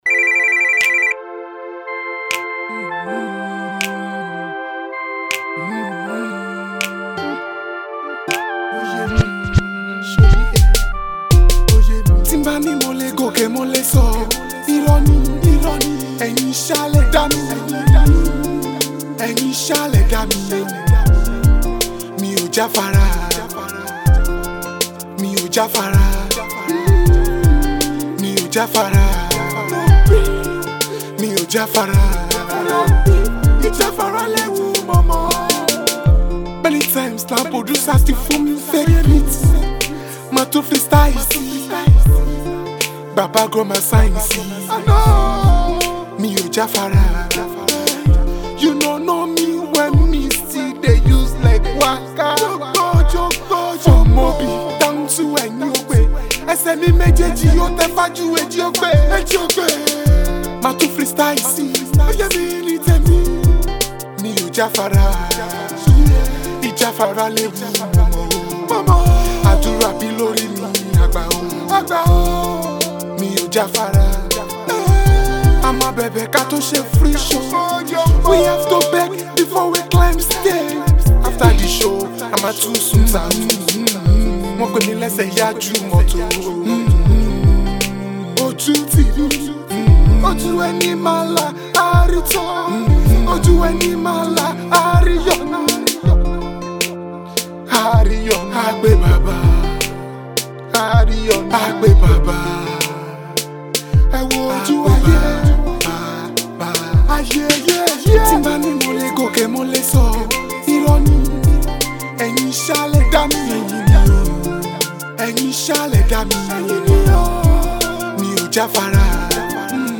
inspiration song